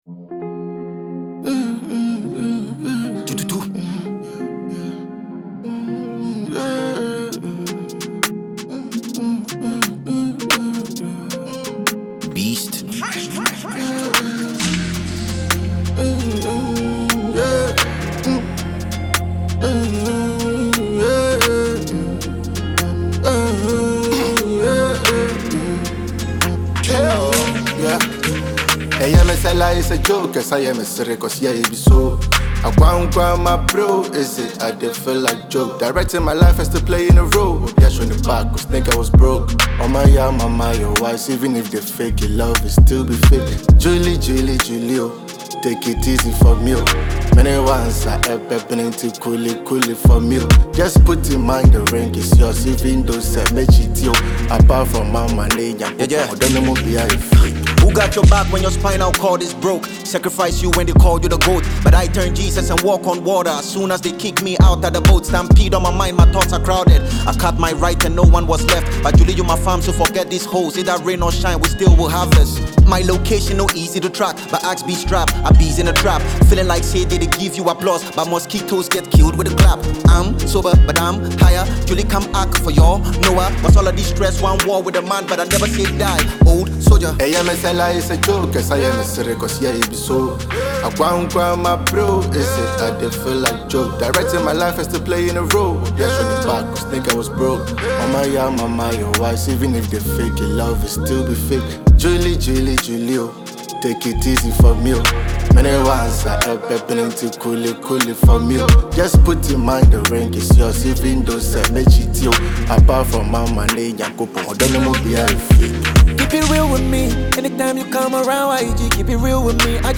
a Ghanaian rapper
studio tune